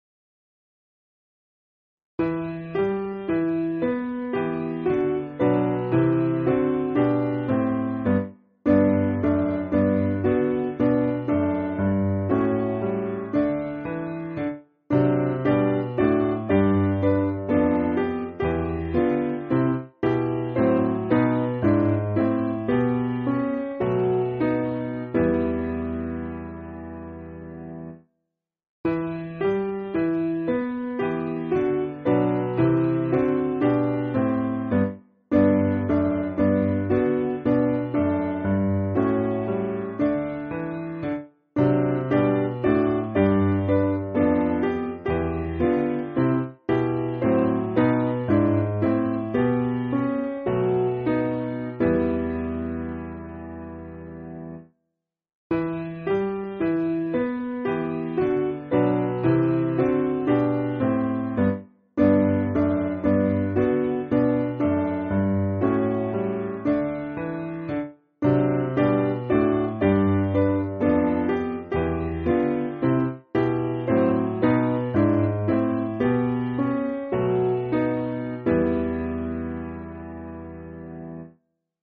Simple Piano
(CM)   4/Em